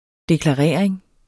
Udtale [ deklɑˈʁεˀɐ̯eŋ ]